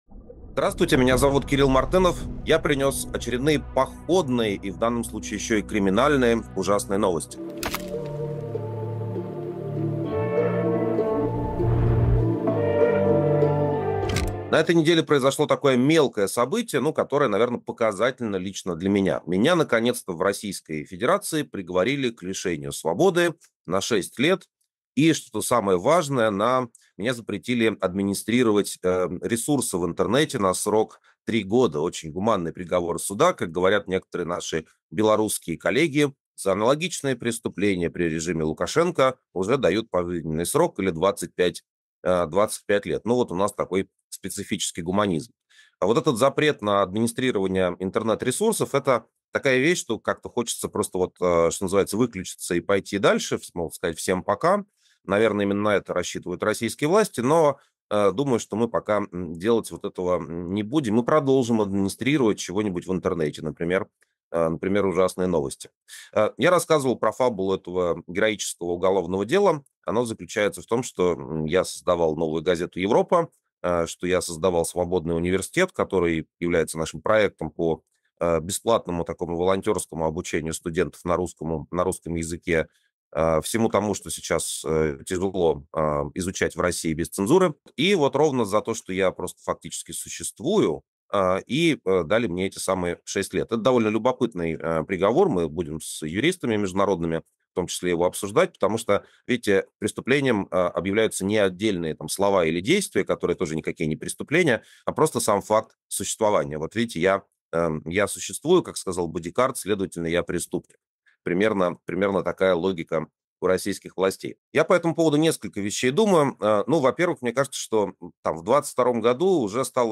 Эфир ведёт Кирилл Мартынов